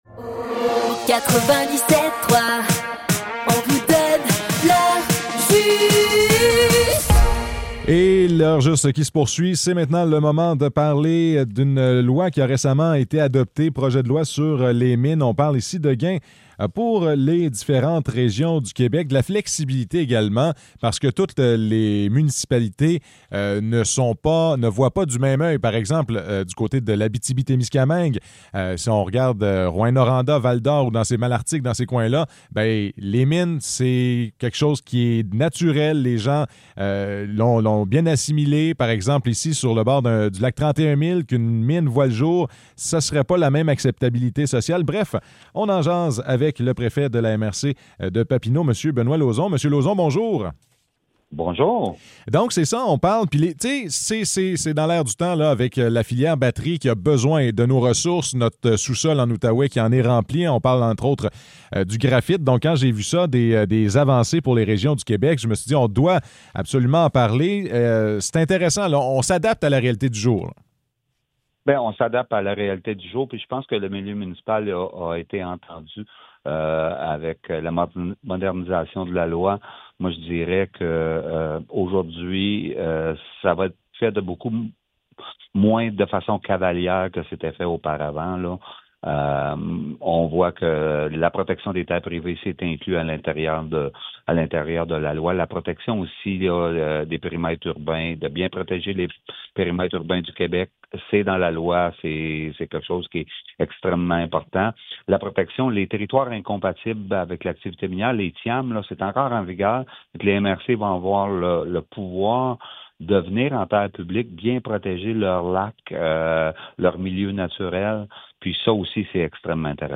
Entrevue avec Benoit Lauzon